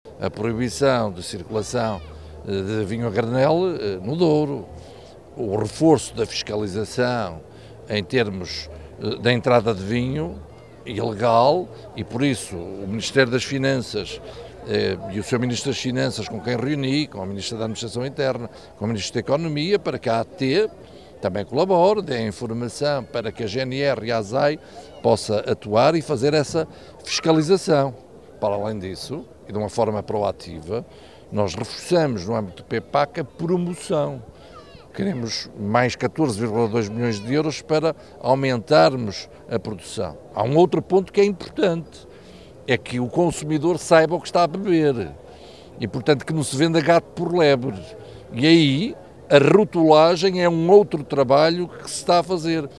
José Manuel Fernandes recordou, no entanto, que o Governo já avançou com outras medidas: